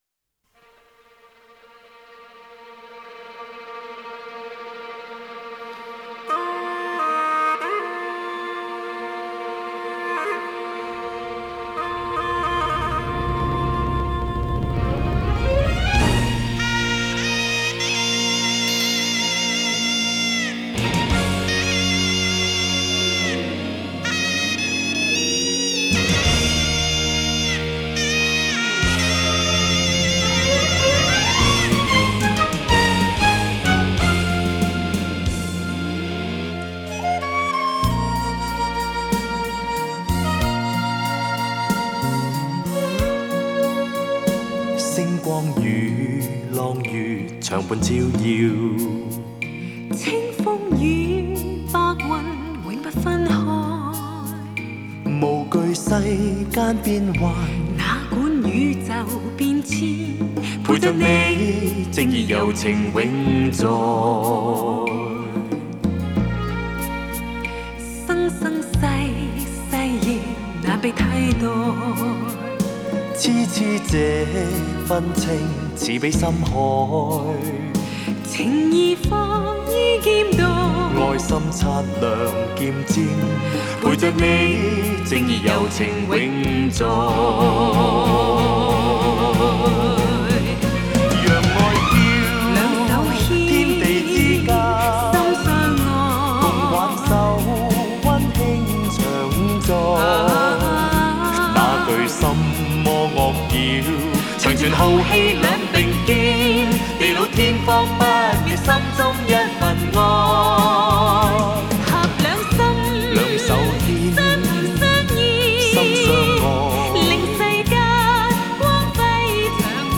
Ps：在线试听为压缩音质节选，体验无损音质请下载完整版 ‌男‌